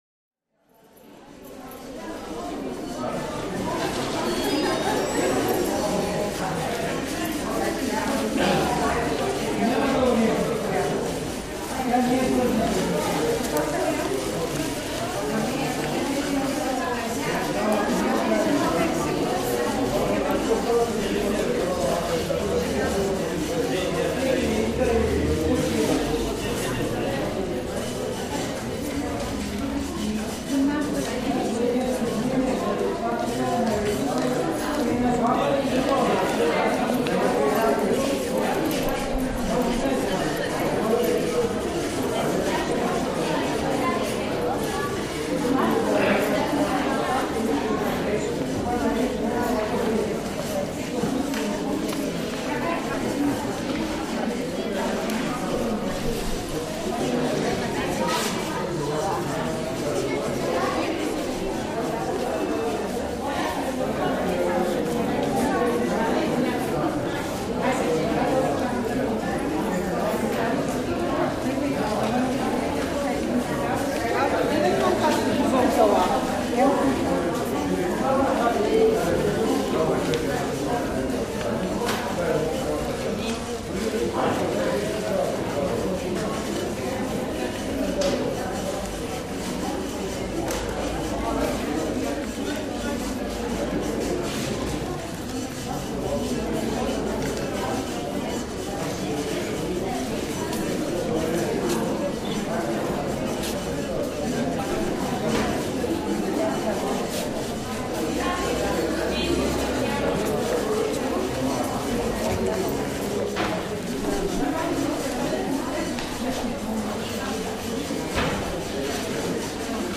State Institution Ambience